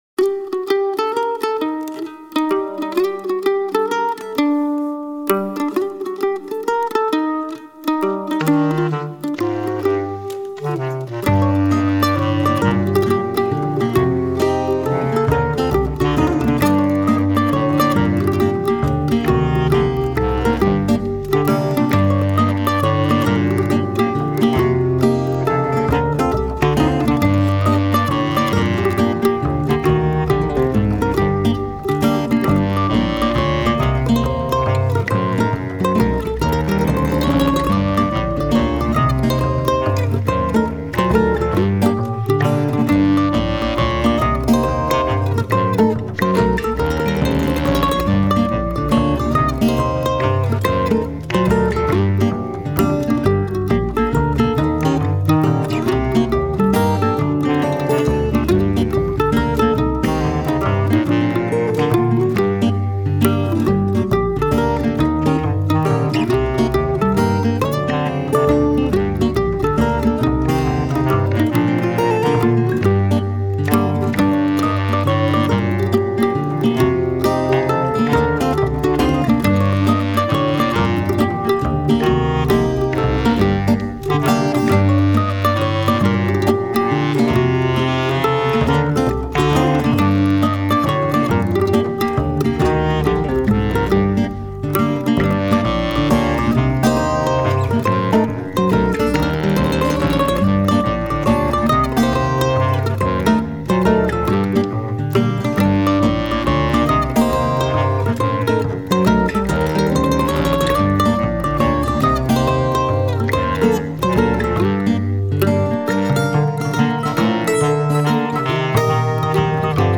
mazurka